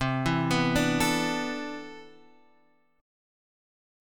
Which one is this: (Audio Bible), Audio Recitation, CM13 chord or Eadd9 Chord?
CM13 chord